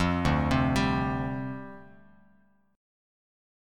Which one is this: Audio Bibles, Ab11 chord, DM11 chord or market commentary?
DM11 chord